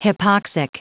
Prononciation du mot hypoxic en anglais (fichier audio)